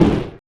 Energy Hit 02.wav